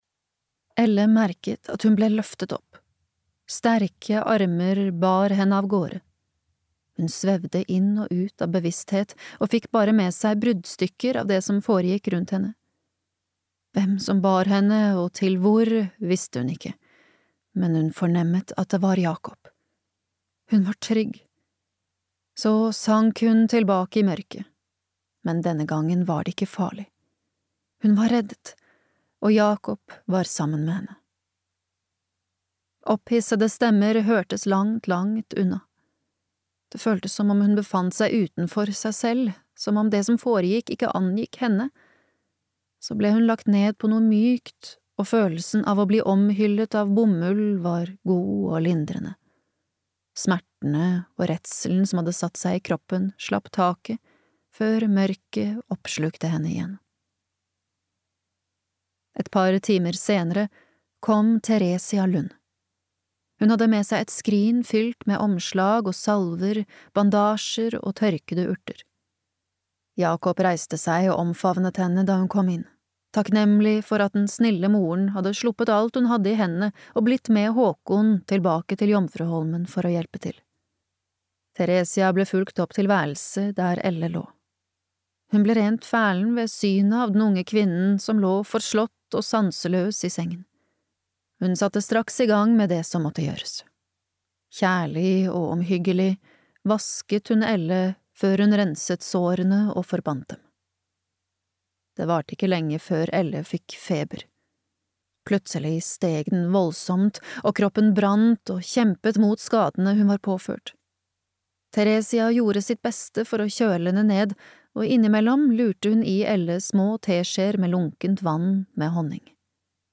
Maktesløs (lydbok)